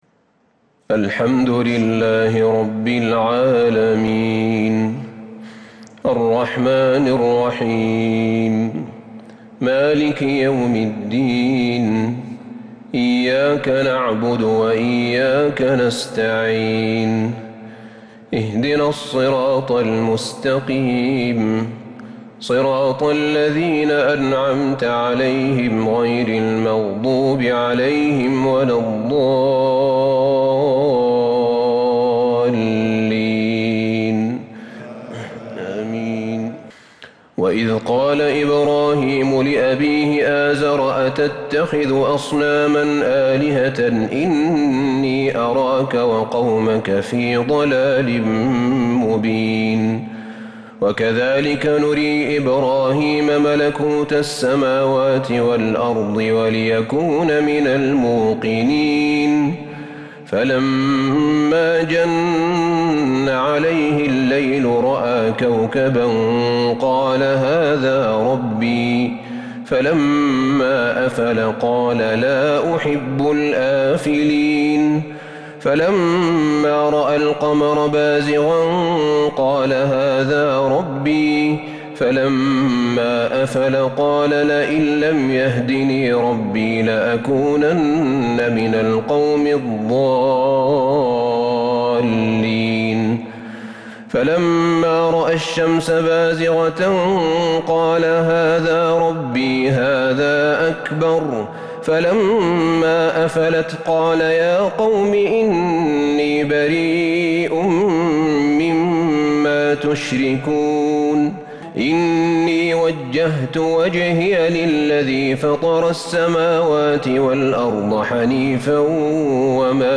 تراويح ليلة 10 رمضان 1442هـ سورة الأنعام (74-135) |taraweeh 10st niqht ramadan Surah Al-Anaam1442H > تراويح الحرم النبوي عام 1442 🕌 > التراويح - تلاوات الحرمين